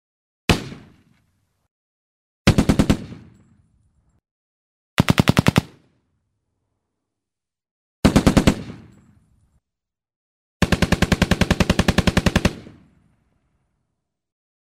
Звуки пулемёта
Перестрелка из пулемётов